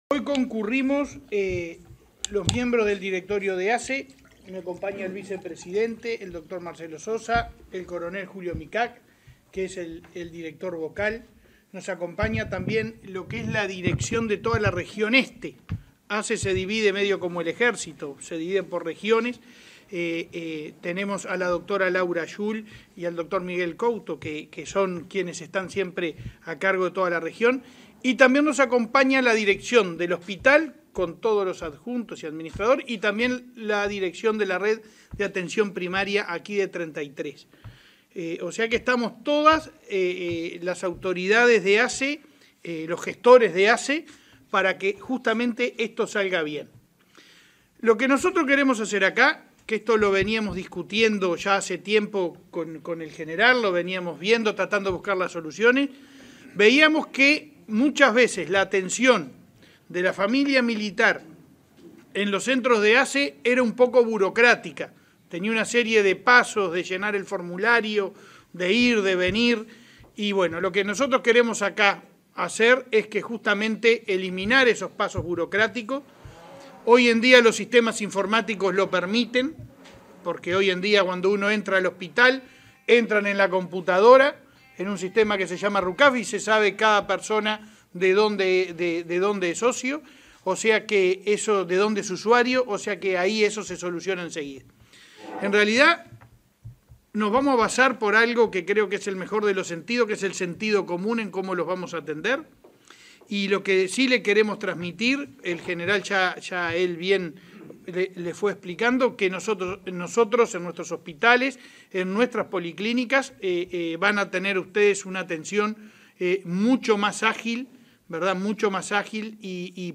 Conferencia de prensa por acuerdo de complementación entre ASSE y Sanidad Militar
El presidente de la Administración de los Servicios de Salud del Estado (ASSE), Leonardo Cipriani, visitó, este 17 de mayo, el hospital de Treinta y Tres, y presentó el convenio de complementación mediante el cual los usuarios de Sanidad Militar podrán atenderse en centros de la ASSE. En la oportunidad Cipriani y el director nacional de Sanidad de las Fuerzas Armadas, Hugo Rebollo, realizaron una conferencia de prensa.